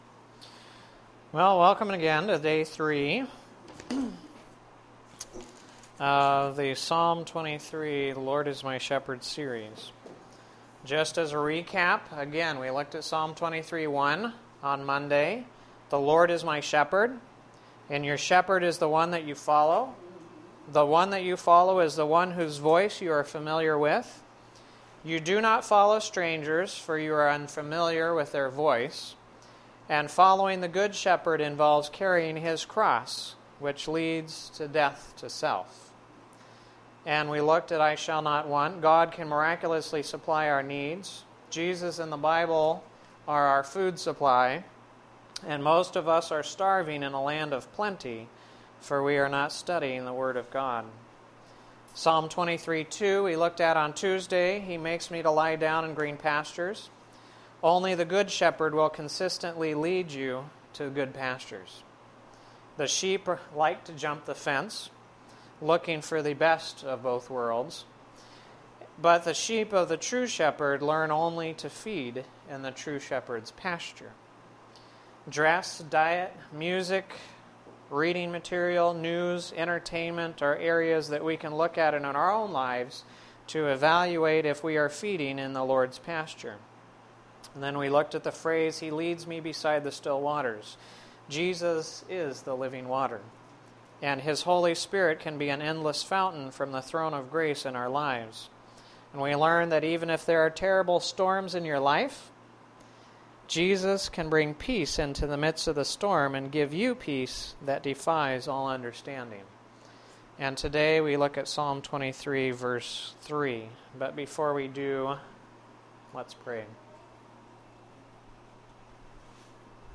This is the third of 5 presentations done at Community Hospital during the week of January 10-14, 2011. The thoughts for this presentation come from Psalm 23 verse 3.